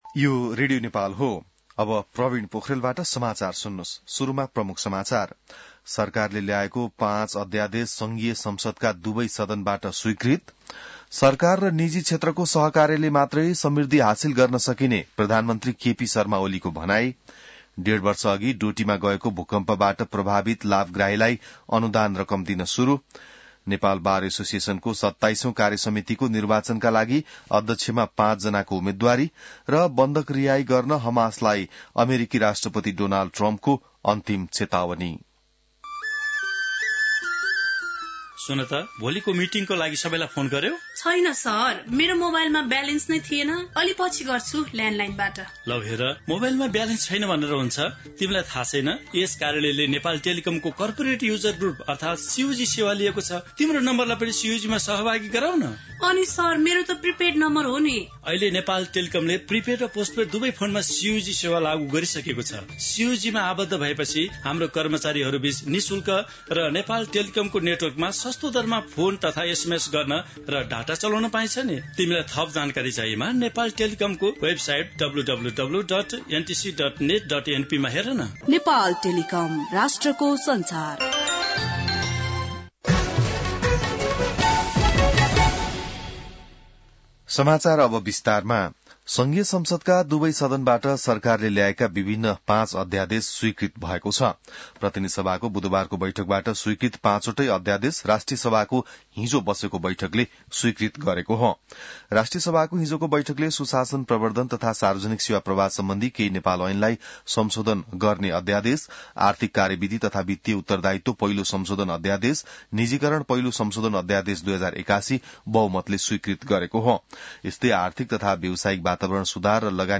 बिहान ७ बजेको नेपाली समाचार : २४ फागुन , २०८१